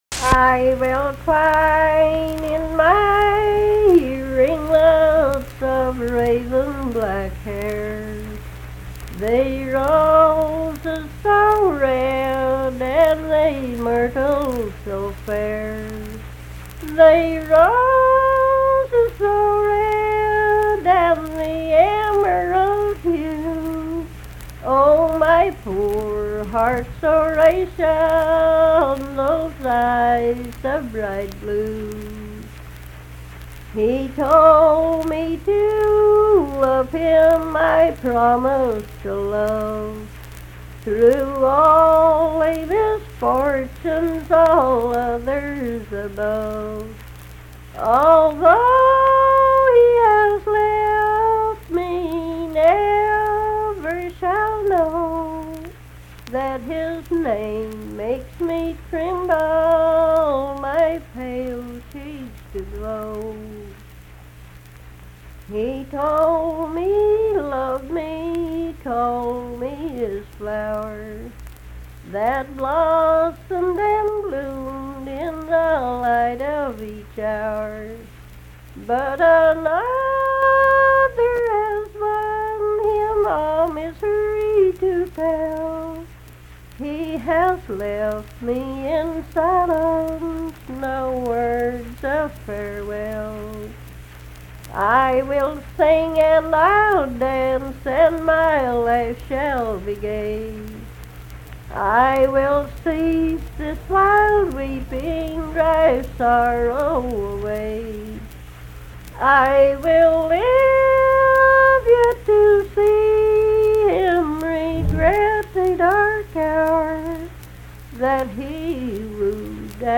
Unaccompanied vocal music performance
Voice (sung)
Braxton County (W. Va.)